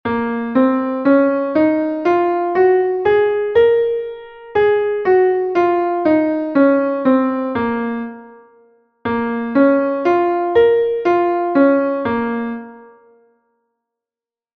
Entoación a capella
Escala e arpexio:
escala_arpegio_sib_menor.mp3